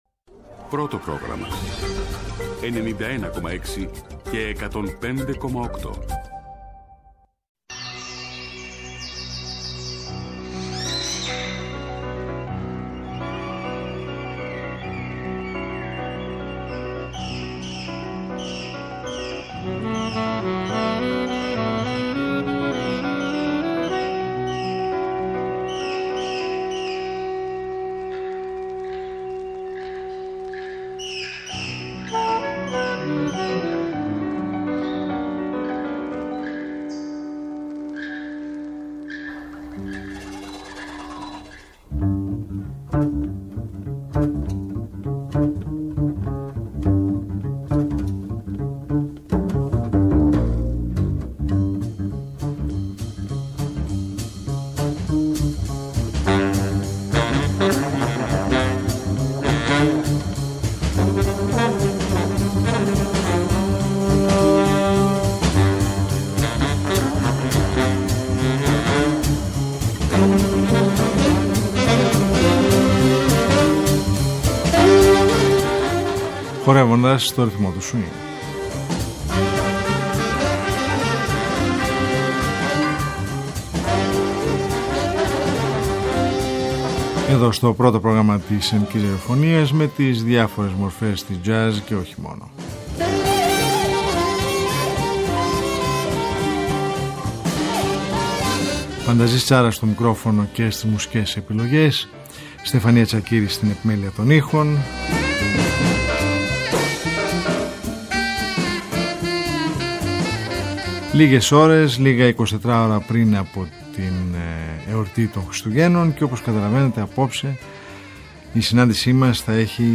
Σας ταξιδεύει με γνωστές Χριστουγεννιάτικες μελωδίες μέσα από τη διεθνή τζαζ μουσική σκηνή, αλλά και αυτή της Ελλάδας, ωρίς να παραβλέπει την μουσική επικαιρότητα των ημερών.